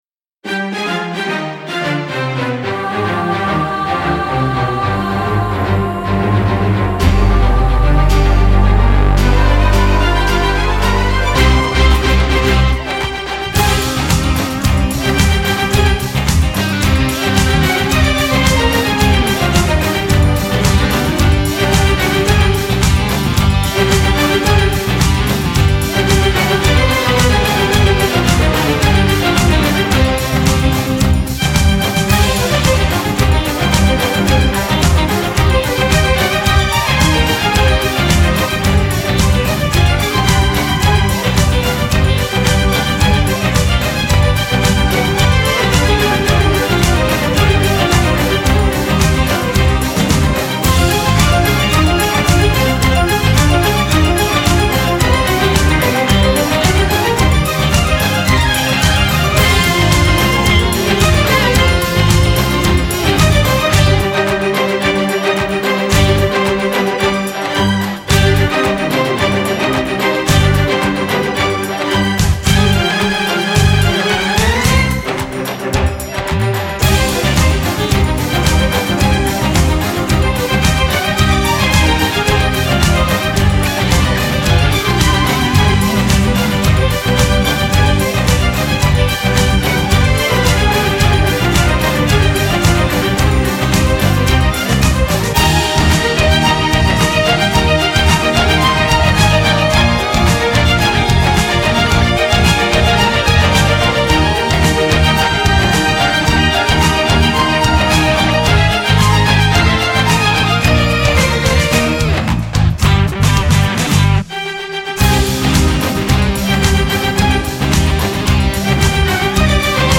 》以强烈的节奏感取胜，洋溢节庆 般的欢愉气氛，令人怦然心动。